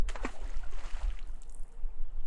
Tag: 性质 飞溅